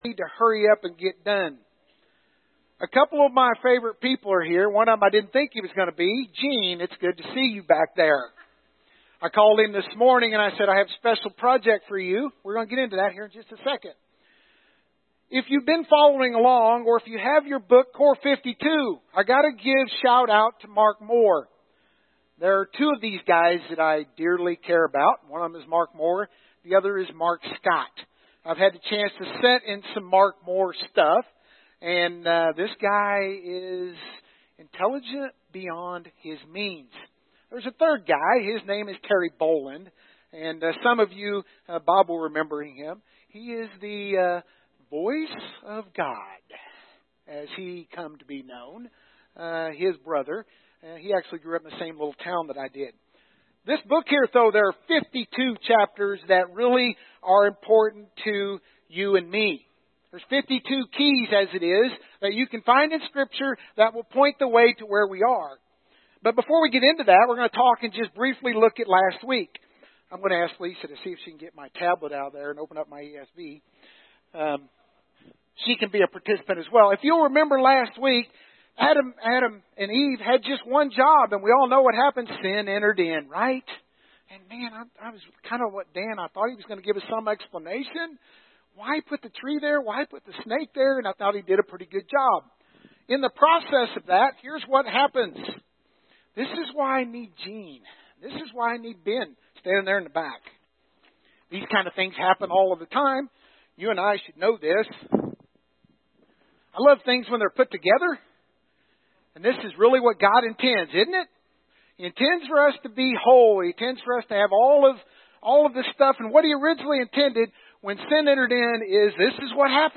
Death Exodus Core 52 Audio Sermon Save Audio After the fall of Adam and Eve sin enters the picture and now nothing looks the same.